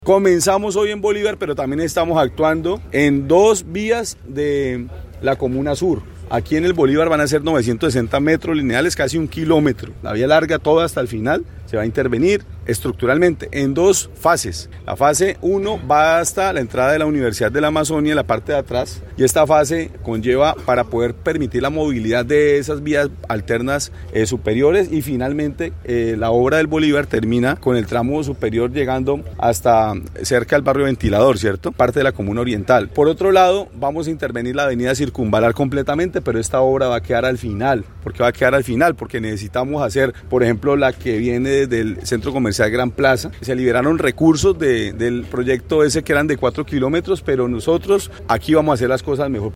Durante la presentación de los trabajos, el alcalde estuvo acompañado por concejales y comunidad, donde reiteró la necesidad de cuidar la obra; tener paciencia y vigilar el desarrollo de los mismos, donde dijo que él mismo será el primer veedor y supervisor, para que las cosas se hagan bien.
ALCALDE_MONSALVE_ASCANIO_BOLIVAR_-_copia.mp3